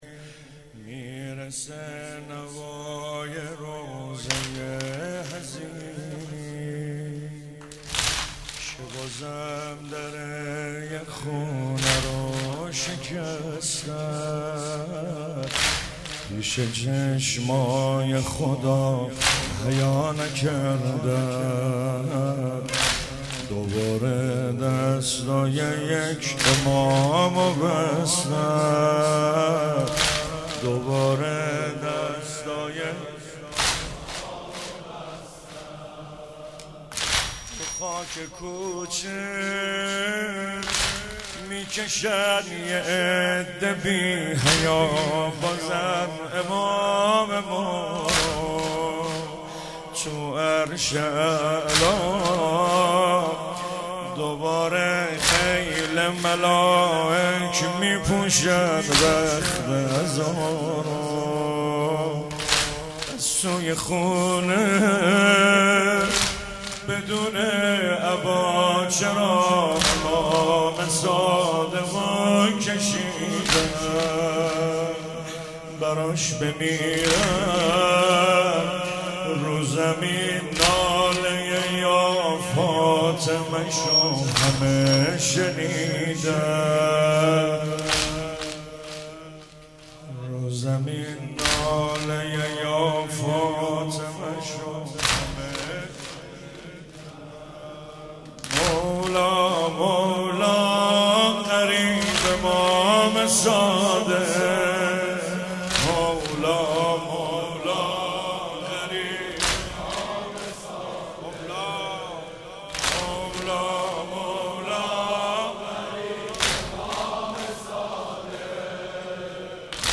هفتگی 21 تیر 97 - واحد - میرسه نوای روضه حزینی